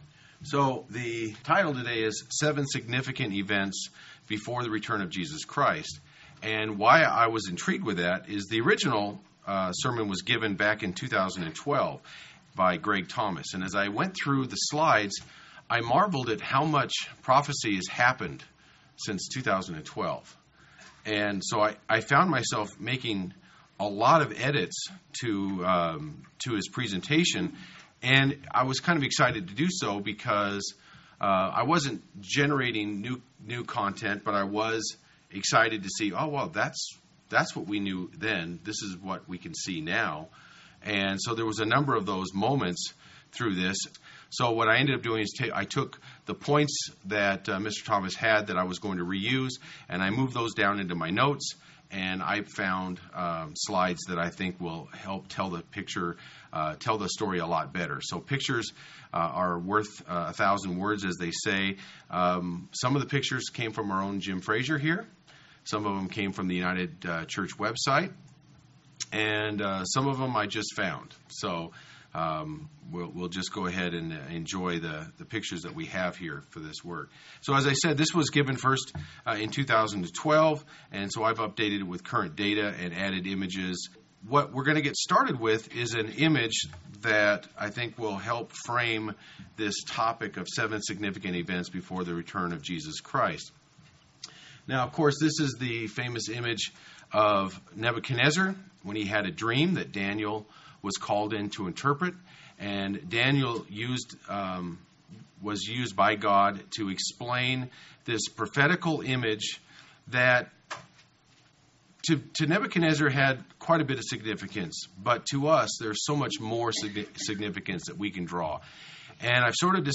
Given in Central Oregon Medford, OR